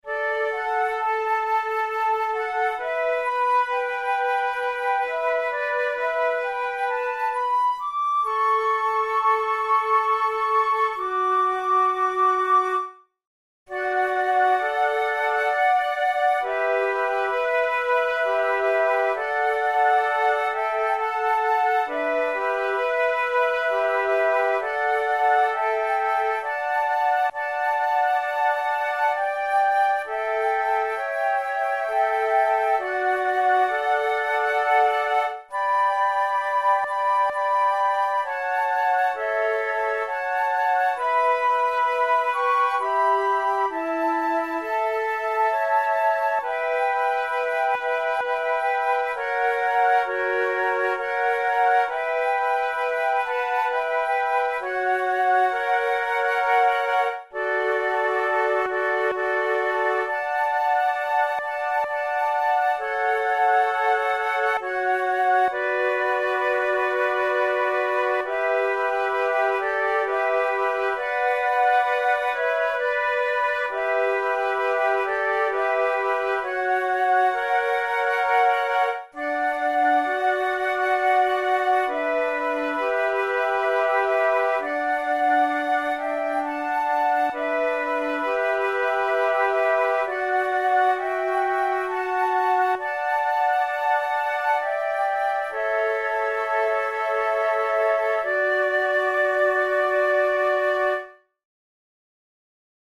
InstrumentationFlute trio
KeyD major
Time signature3/4
Tempo66 BPM
20th century, Christmas carols
arranged for three flutes
jul-jul-stralande-jul-trio.mp3